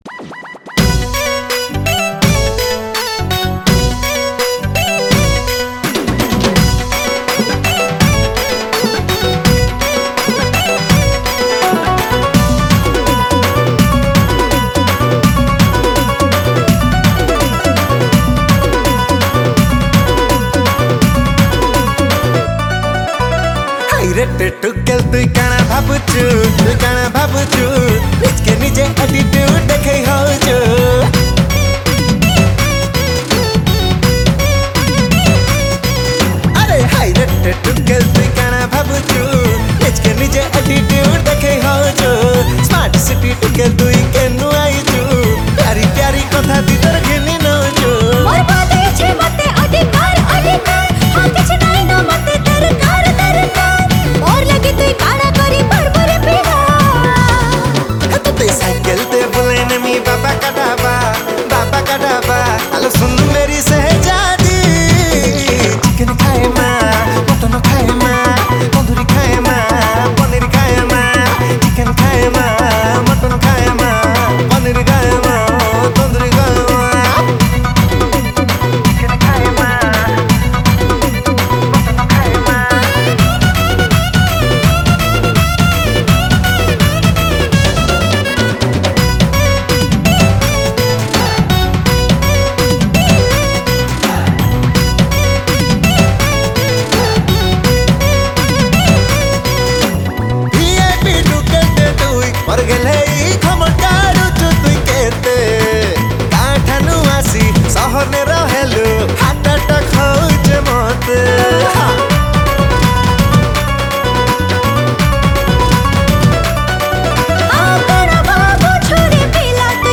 Category: New Sambalpuri Folk Song 2021